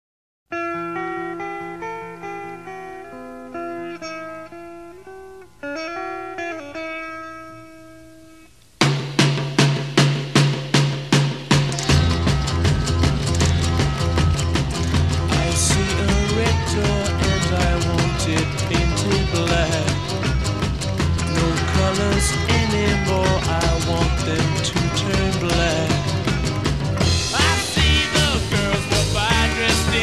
Middle Eastern-influenced